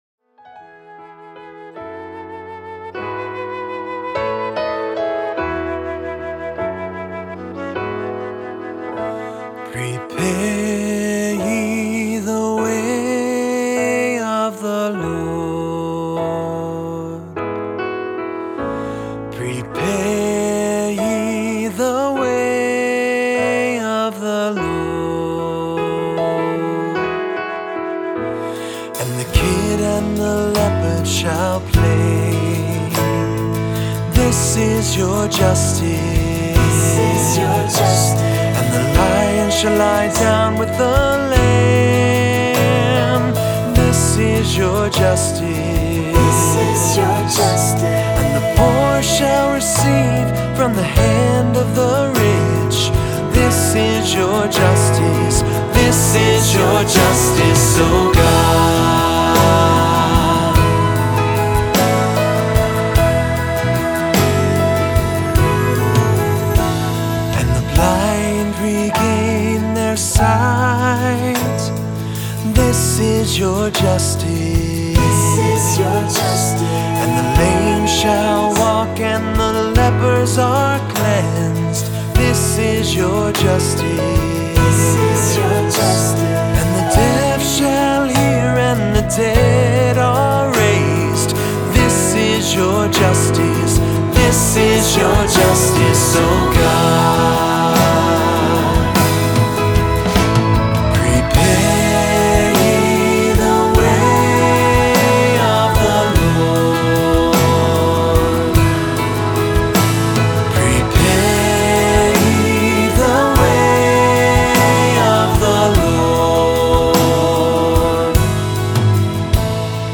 Voicing: Three-part equal; Cantor; Assembly